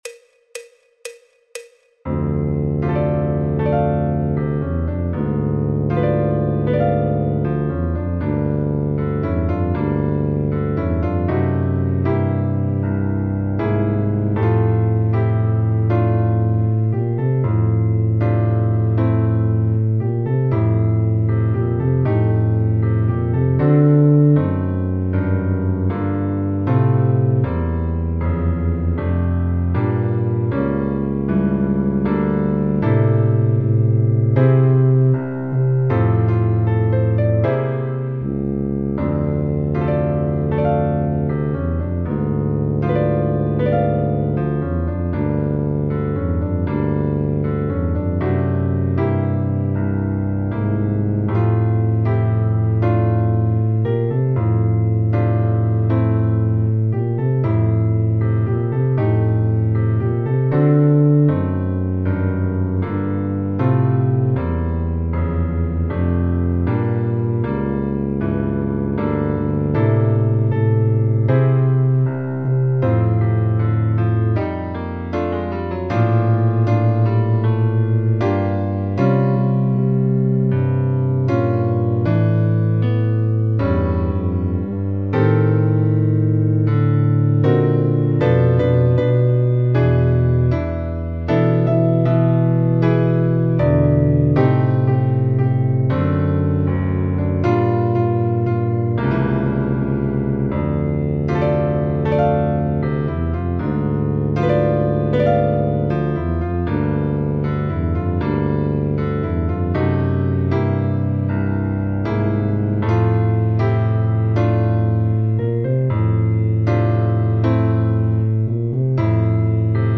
El MIDI tiene la base instrumental de acompañamiento.
Tuba and Double Bass in F Major Jazz Beginner Level
Contrabajo, Tuba
Fa Mayor
Jazz, Popular/Tradicional